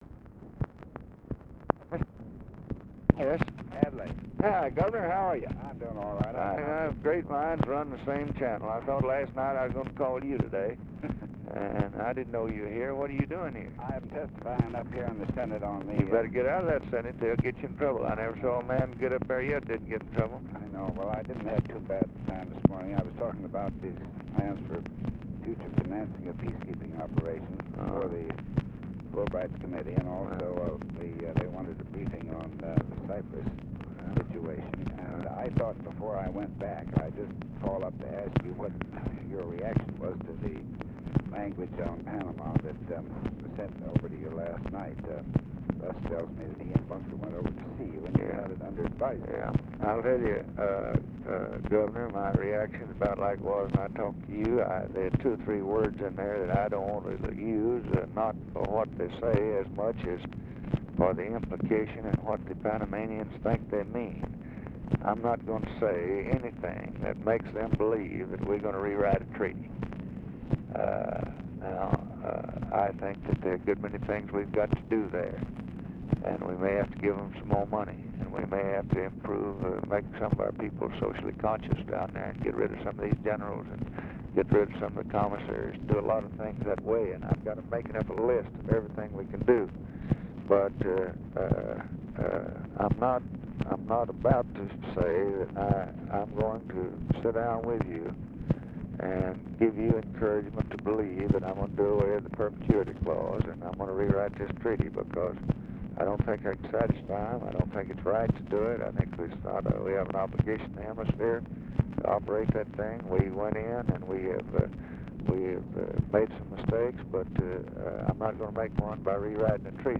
Conversation with ADLAI STEVENSON, February 26, 1964
Secret White House Tapes